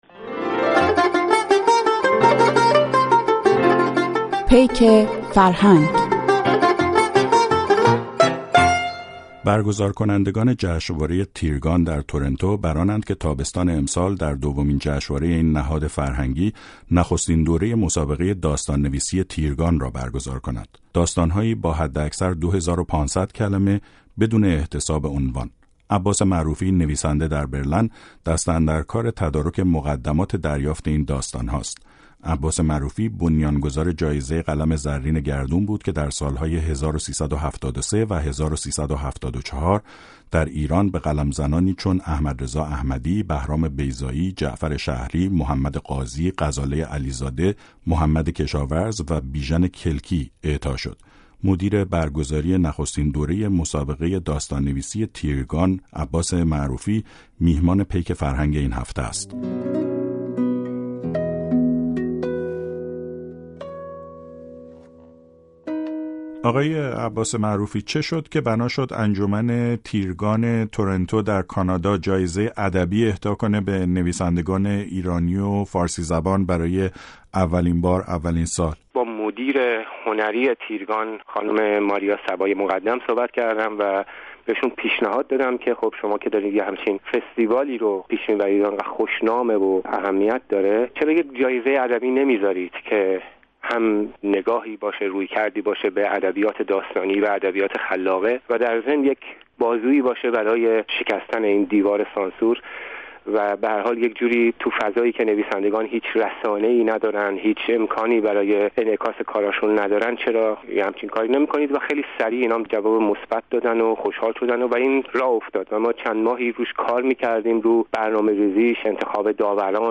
چند و چون نخستین جایزه ادبی تیرگان در گفت‌وگو با عباس معروفی